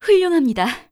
cleric_f_voc_social_03.wav